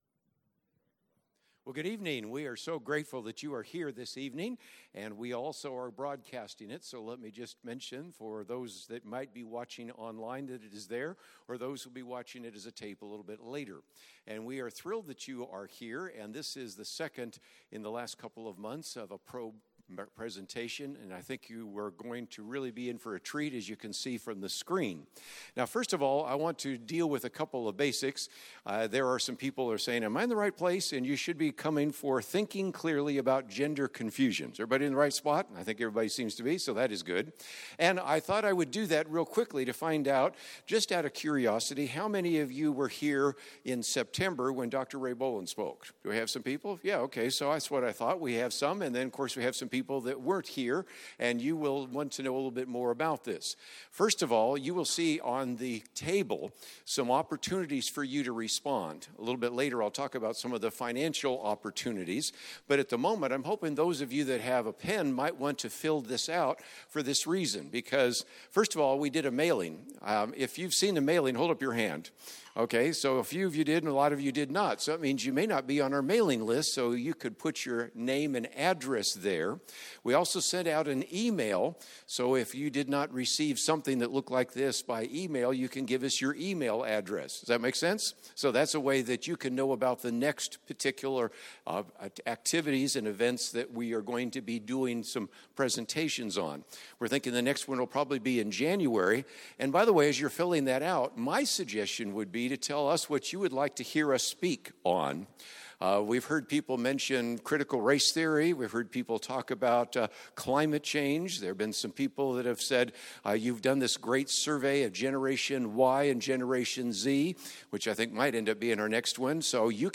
Probe presents biblical worldview thinking about gender and its forms of brokenness at an in-person and Facebook Live event 11/18/21.